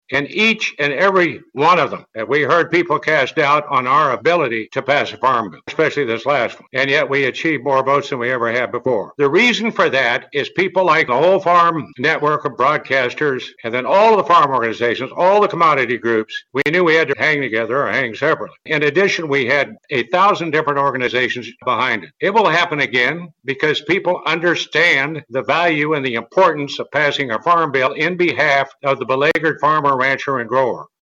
Senate Ag Committee Chair Pat Roberts, speaking during an Agri-Pulse event, will not seek re-election this year.